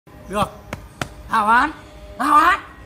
Thể loại: Câu nói Viral Việt Nam
am-thanh-meme-duoc-hao-han-hao-han-phu-le-www_tiengdong_com.mp3